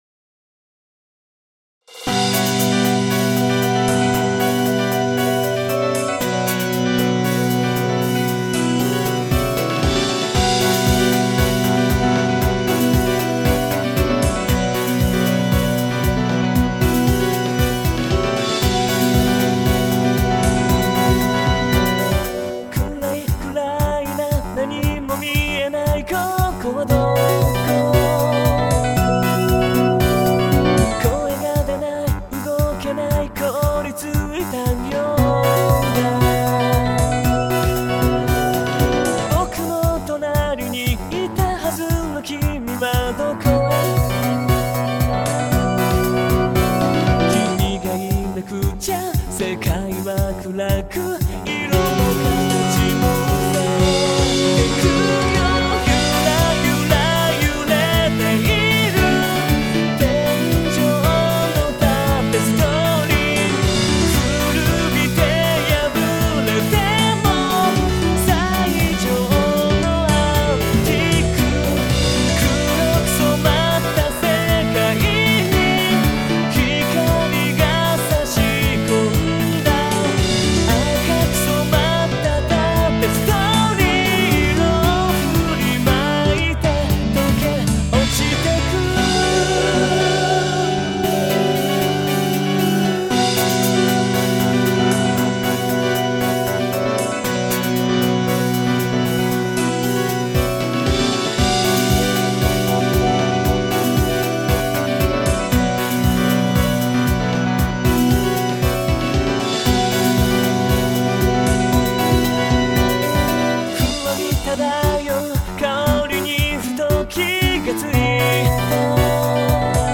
[歌モノ] オブリビオン・タペストリー
創作世界の住人とその創作主、お別れの歌。VoiSona「梵そよぎ」によるオリジナルソング。
4分10秒 / ボーカル使用ライブラリ : 梵そよぎ（VoiSona）